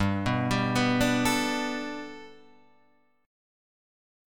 G Major Add 9th